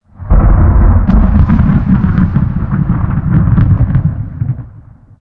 thunder33.ogg